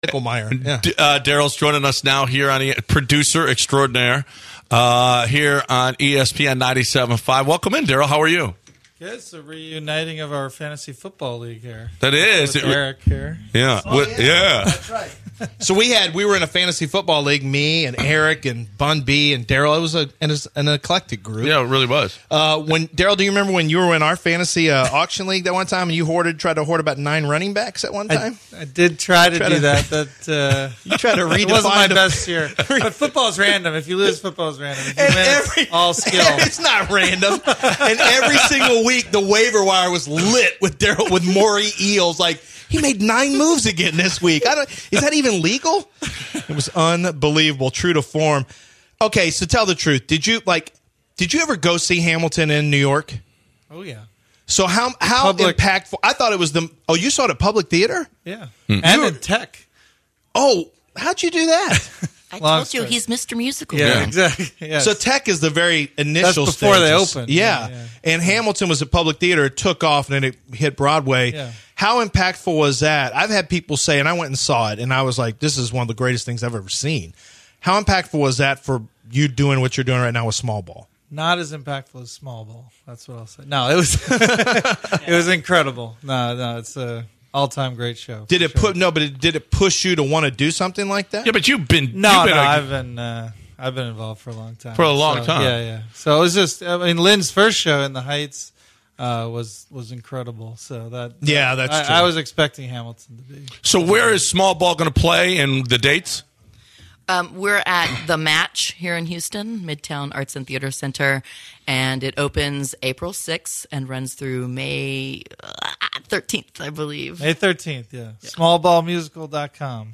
Daryl Morey Joins the Studio